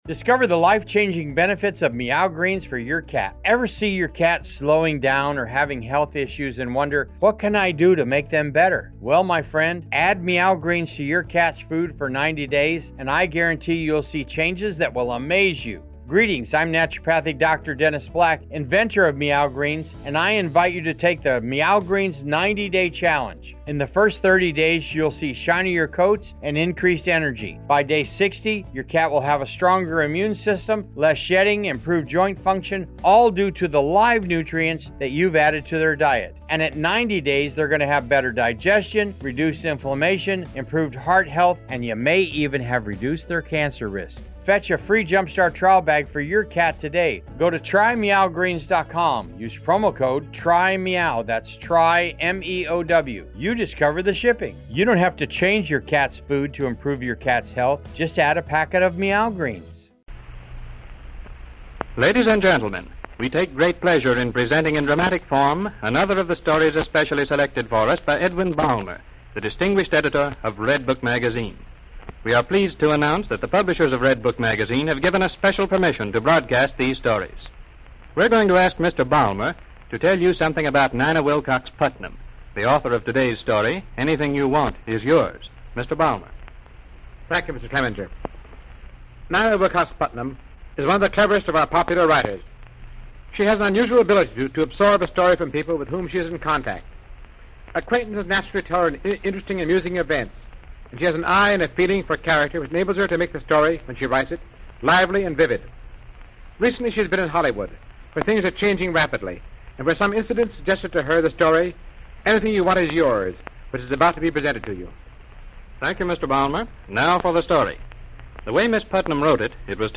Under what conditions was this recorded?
'Redbook Dramas,' a radio series that aired in 1932, offered a blend of love, mystery, adventure, and romance, all based on short stories originally published in Redbook Magazine.